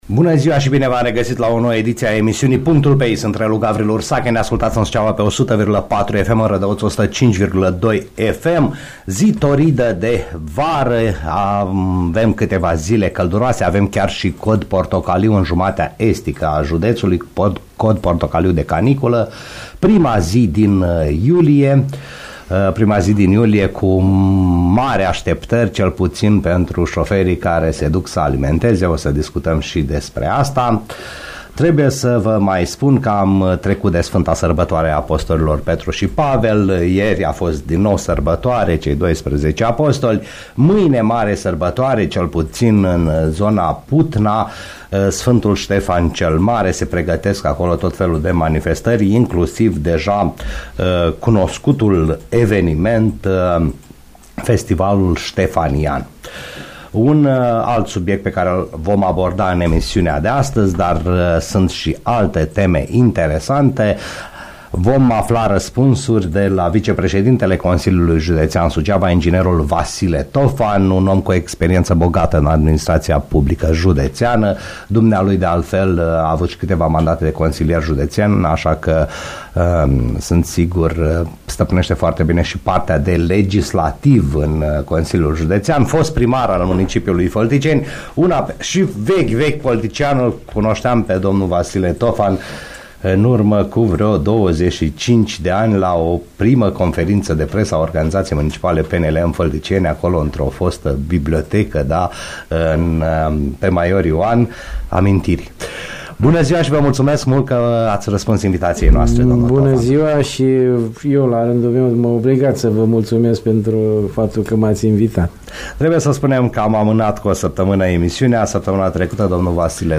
Vicepreședintele Consiliului Județean Suceava Vasile Tofan invitat la PUNCTUL PE I